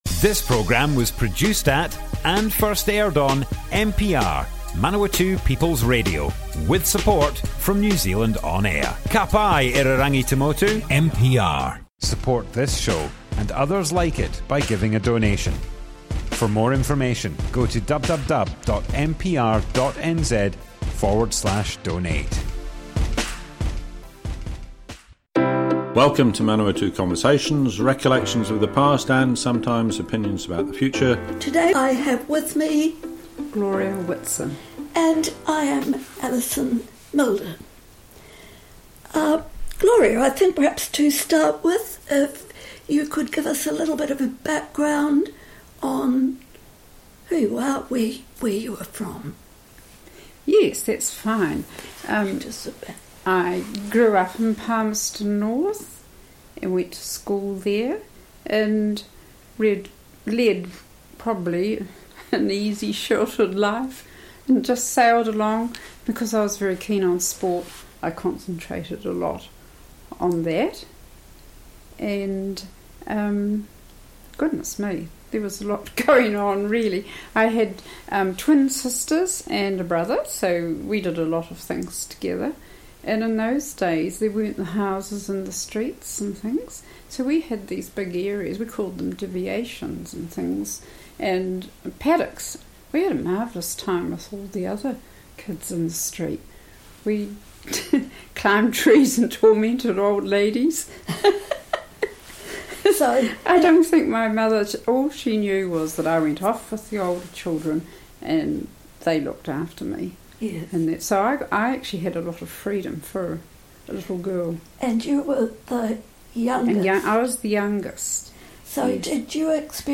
Manawatu Conversations More Info → Description Broadcast on Manawatu People's Radio, 21st June 2022. Growing up in Palmerston North in the 1950s.
oral history